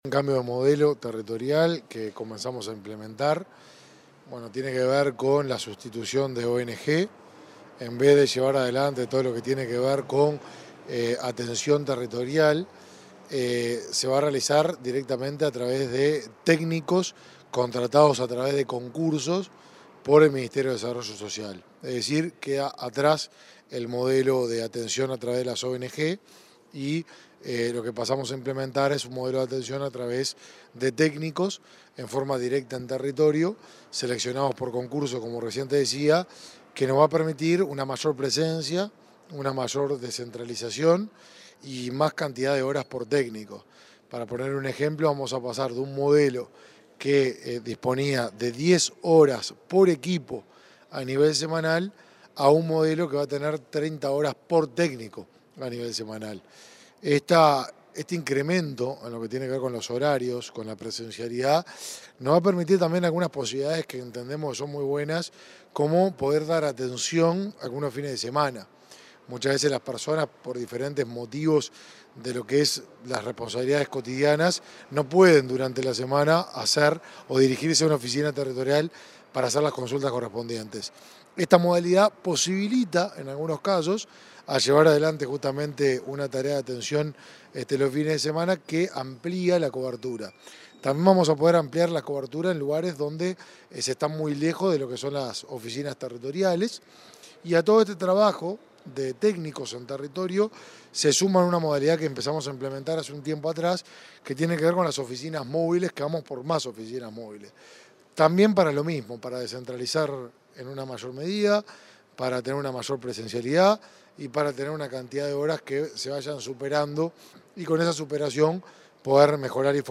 Entrevista al ministro de Desarrollo Social, Martín Lema
El ministro de Desarrollo Social, Martín Lema, dialogó con Comunicación Presidencial sobre el nuevo modelo territorial de esa cartera.